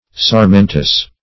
sarmentous - definition of sarmentous - synonyms, pronunciation, spelling from Free Dictionary Search Result for " sarmentous" : The Collaborative International Dictionary of English v.0.48: Sarmentous \Sar*men"tous\, a. (Bot.)